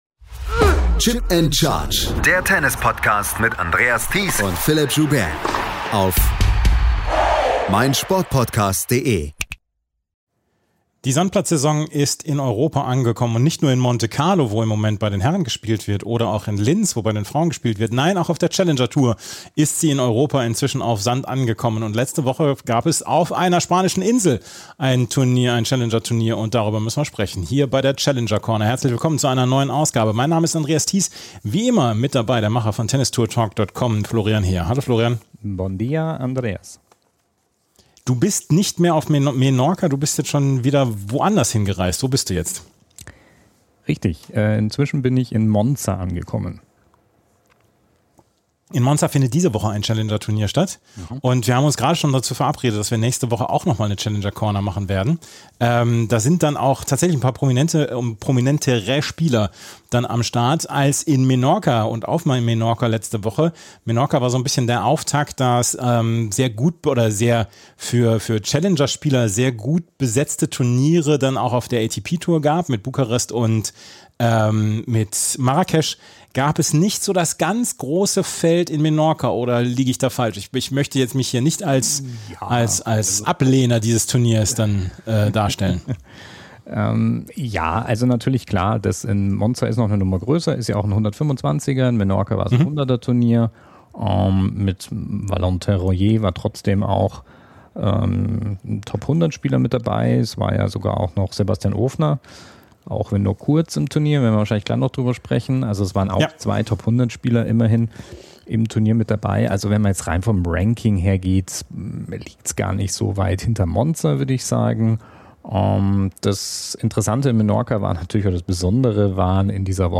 war vor Ort und hat mit den Protagonisten gesprochen.